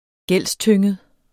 Udtale [ -ˌtøŋˀəð ]